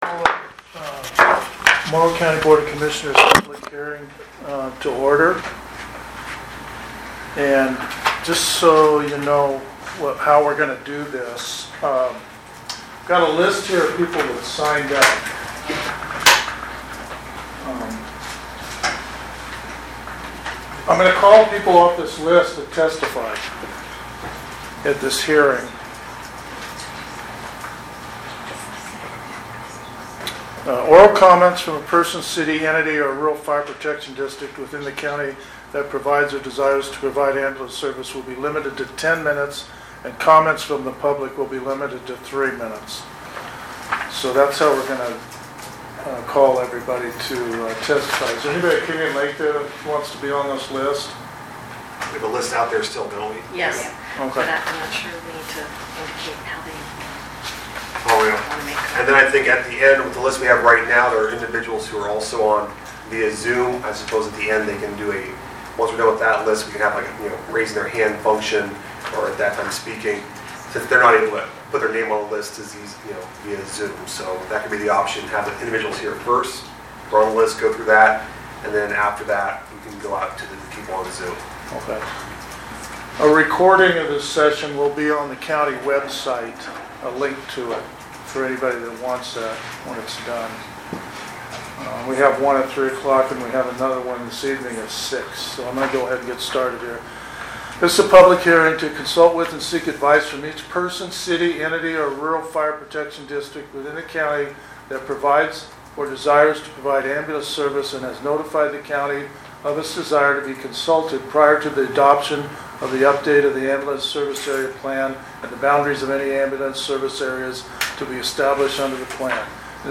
Board of Commissioners Public Hearing - Heppner | Morrow County Oregon